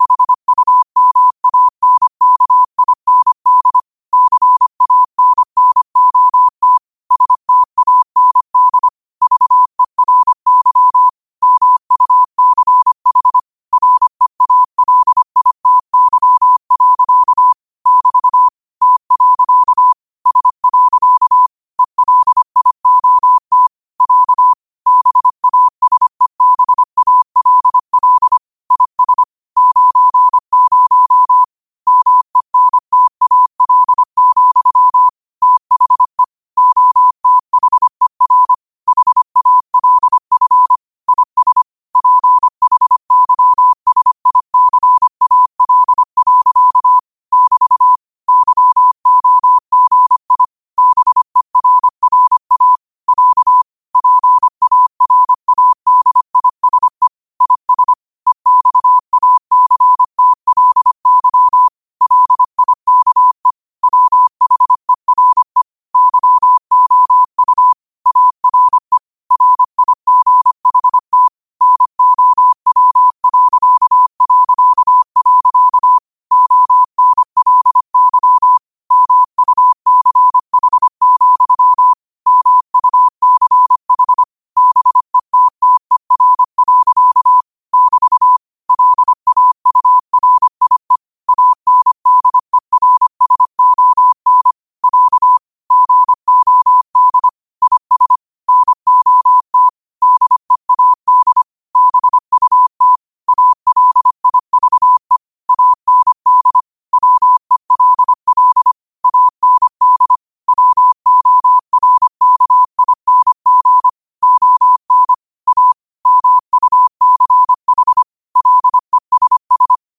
Quotes for Thu, 14 Aug 2025 in Morse Code at 25 words per minute.